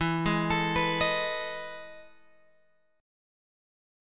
EM11 chord {12 11 x 14 12 11} chord